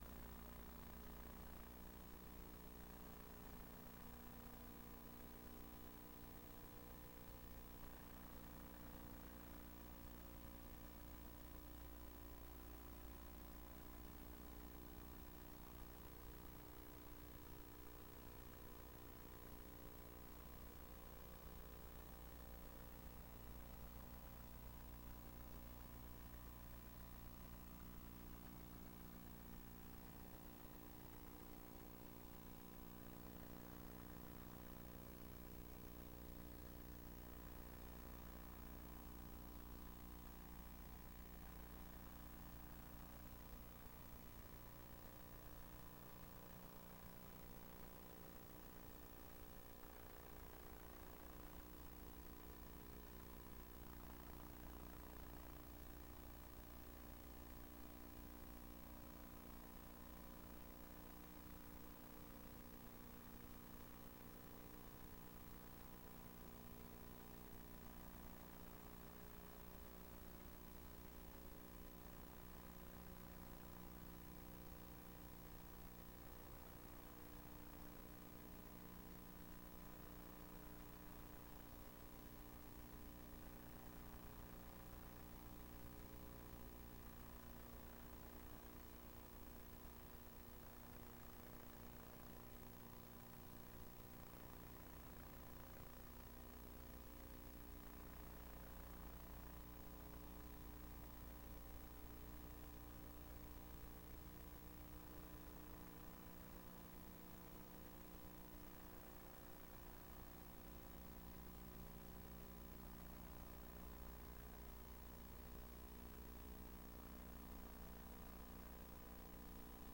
Ephesians ch 2 vs 1-10 - Preacher